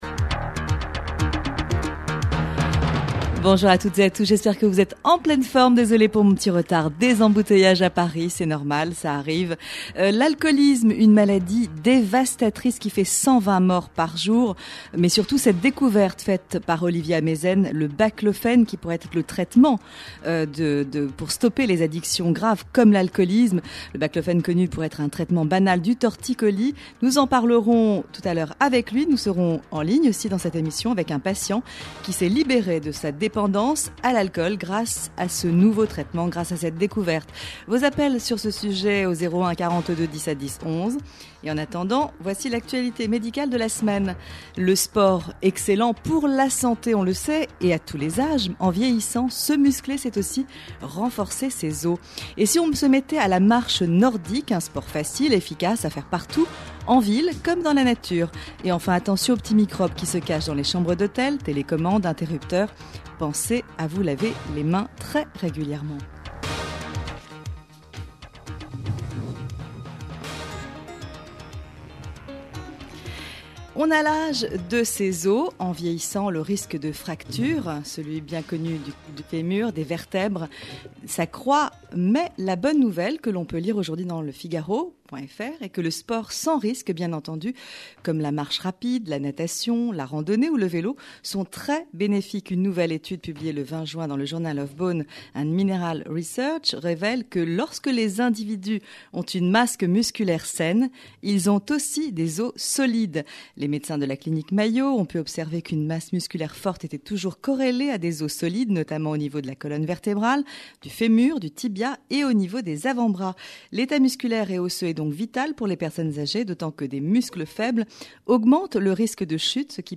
Interview du Pr Olivier Ameisen
sur la radio de la communauté juive